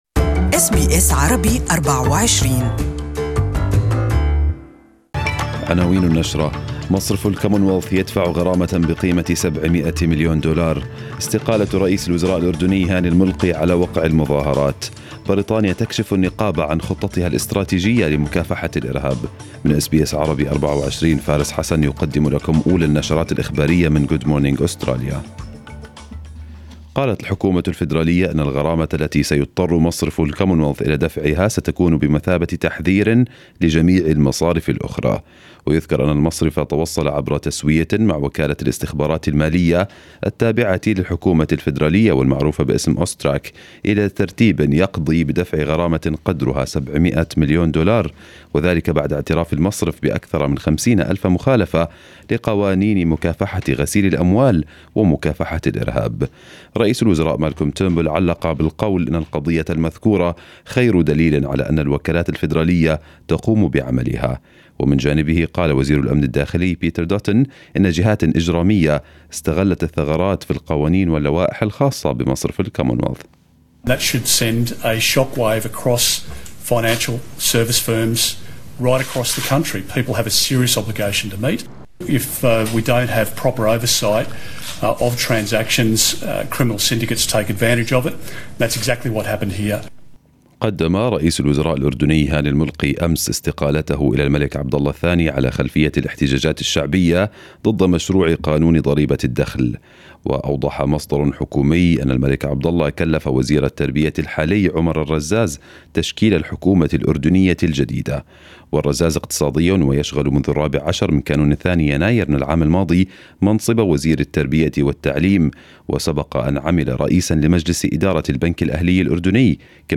Arabic News Bulletin 05/06/2018